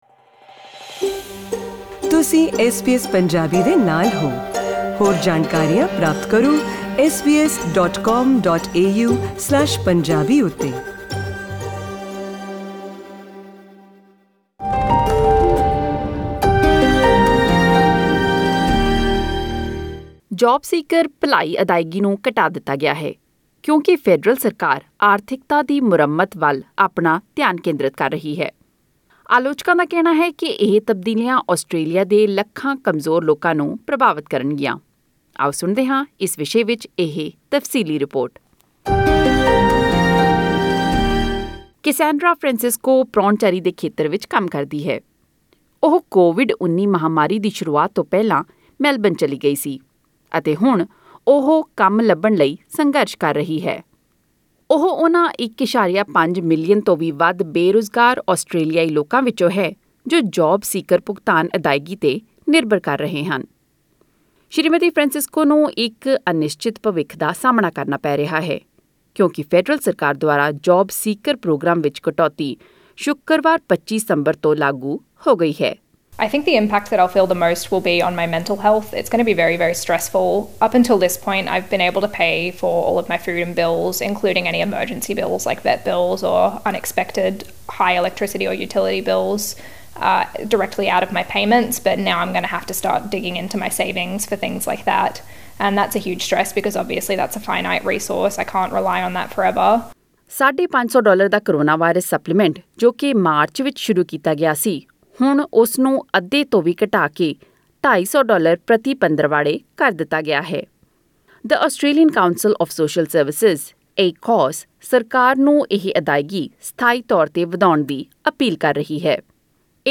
To hear the full report click on the audio link above.